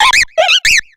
Grito de Ambipom.ogg
Grito_de_Ambipom.ogg